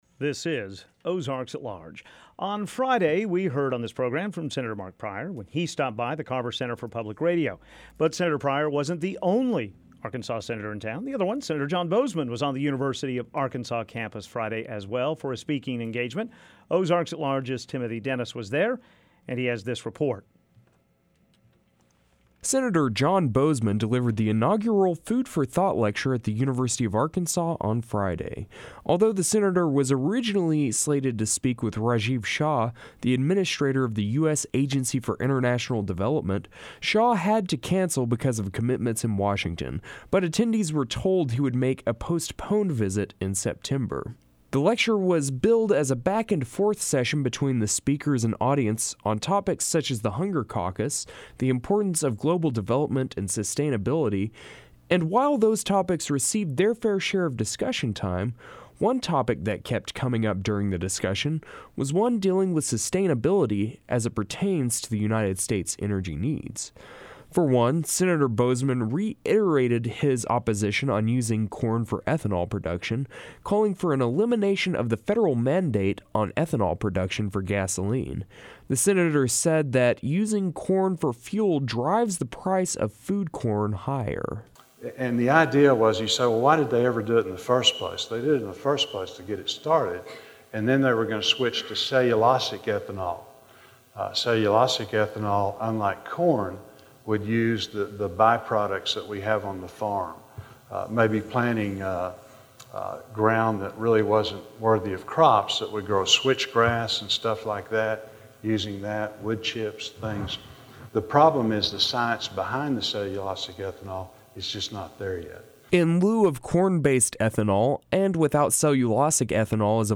Senator John Boozman delivered the inaugural lecture in the food for Thought lecture series, presented by the Dale Bumpers College of Agricultural, food and Life Sciences. The discussion focused on many topics, one of which was fuel. And afterwards, the senator talked about his thoughts on the budget and how long sequestration will last.